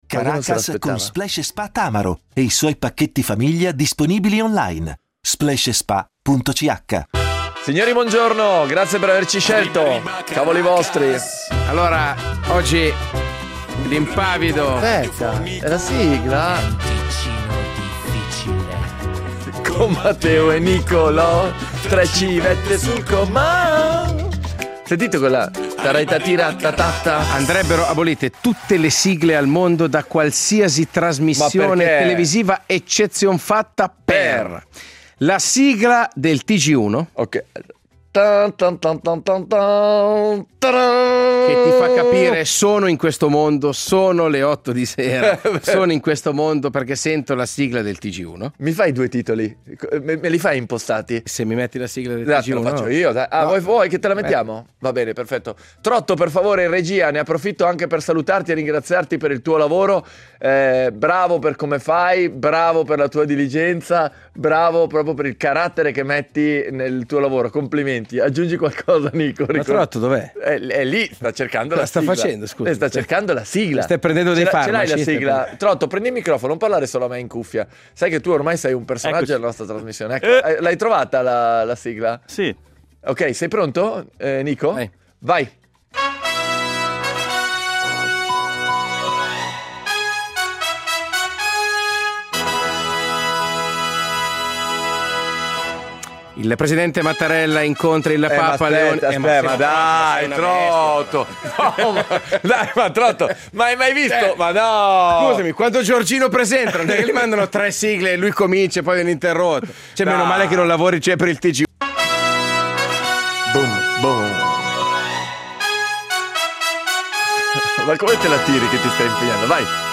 improvvisa una carrellata di sue sigle preferite di “ogni epoca” (cit.) a cui ovviamente si agganciano imitazioni e colonne sonore di serie TV e film anni 80-90.
chiamato mentre è ai controlli di sicurezza dell’aeroporto.